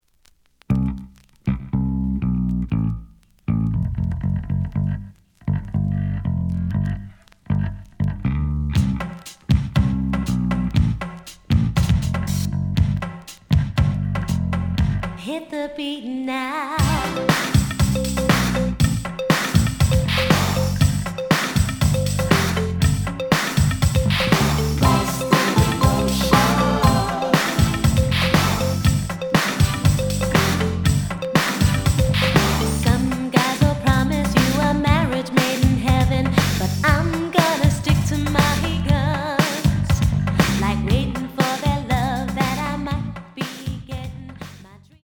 The audio sample is recorded from the actual item.
●Genre: Funk, 80's / 90's Funk
Slight noise on beginning of both sides, but almost good.